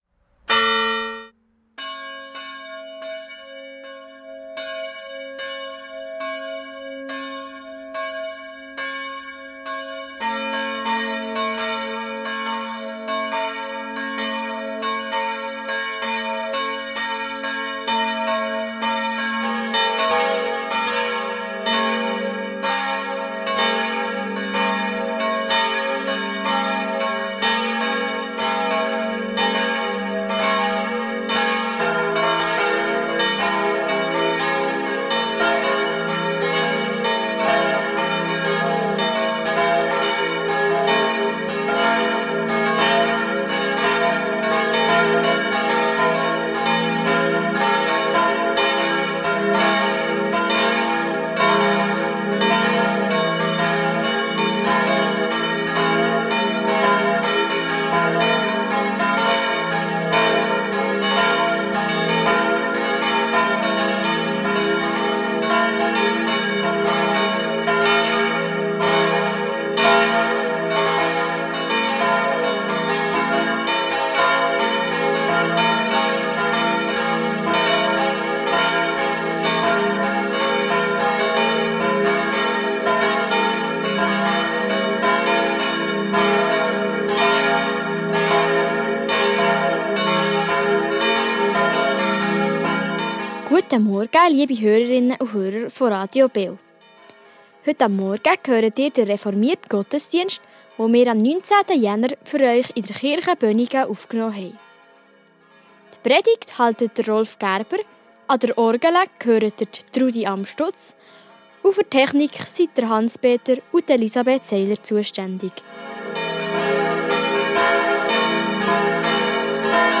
Reformierte Kirche Bönigen ~ Gottesdienst auf Radio BeO Podcast